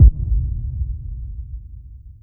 001-boomkick.wav